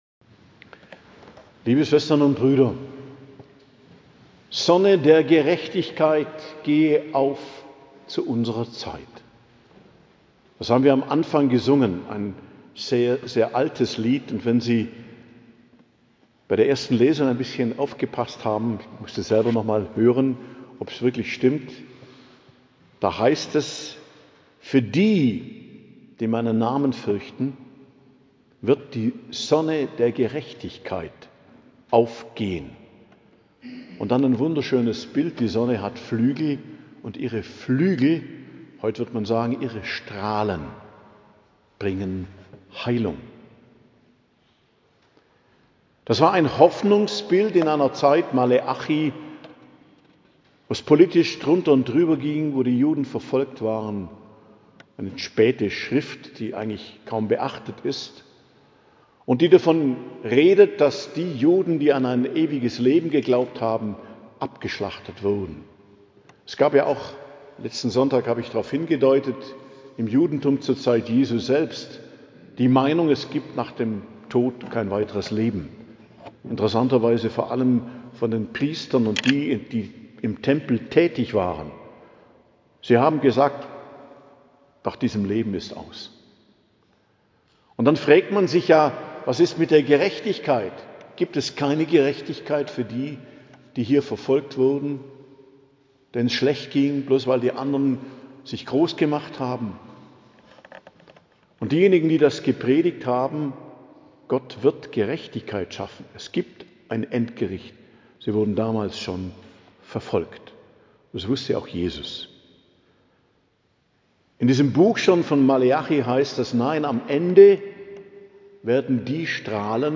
Predigt zum 33. Sonntag i.J., 16.11.2025